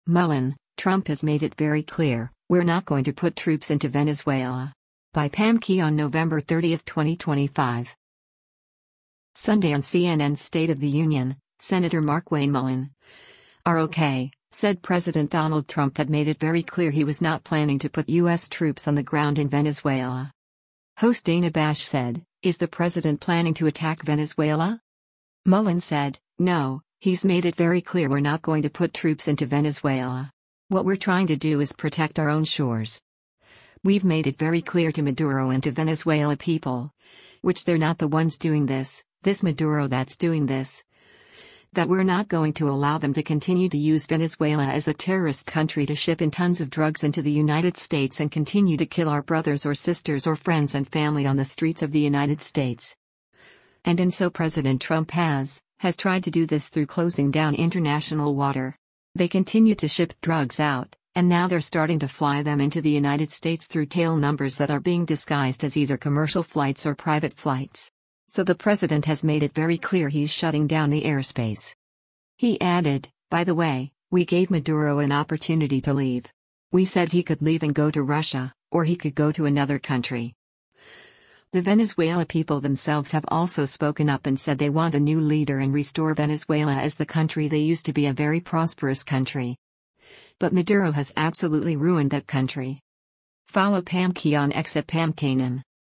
Sunday on CNN’s “State of the Union,” Sen. Markwayne Mullin (R-OK) said President Donald Trump had made it “very clear” he was not planning to put U.S. troops on the ground in Venezuela.